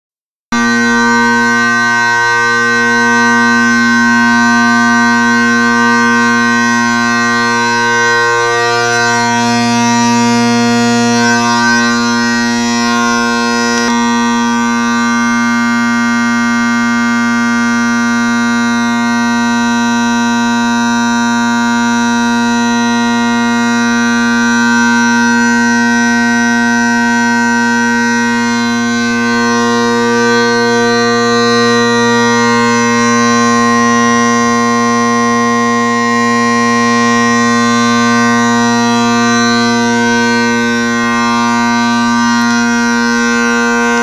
Douglas MacPherson drones + Canning drone reeds
I must say, these are some wonderful sounding drones. They blend very well and have a very warm, pleasing tone.
drones.wma